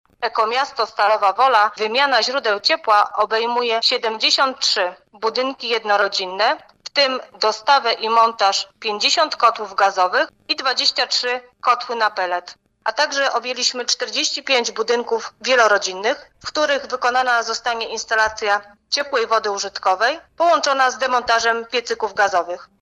Mówiła o tym wiceprezydent Stalowej Woli Renata Knap: